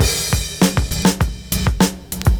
100CYMB06.wav